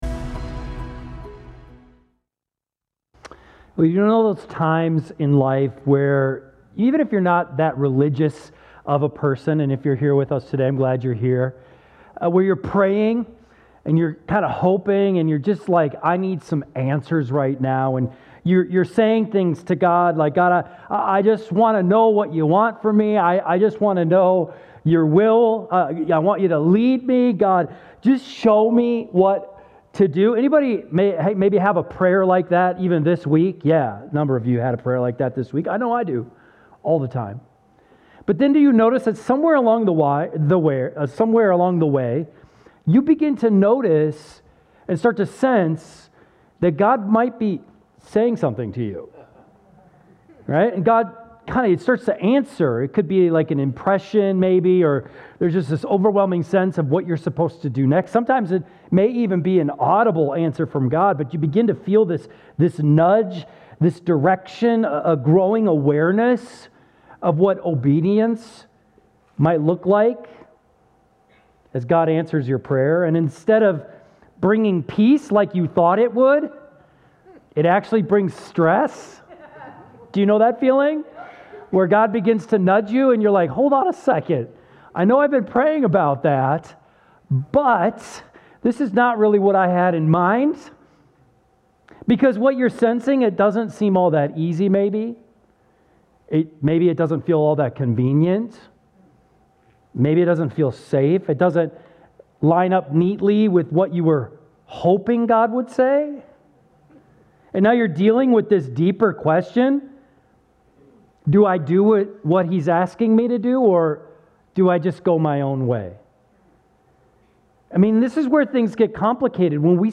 keyboard_arrow_left Sermons / James Series Download MP3